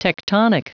Prononciation du mot tectonic en anglais (fichier audio)
Prononciation du mot : tectonic